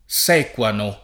[ S$ k U ano ]